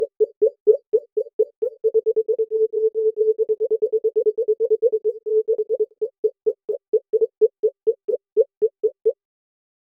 low-volume-subtle-process-mlyszptz.wav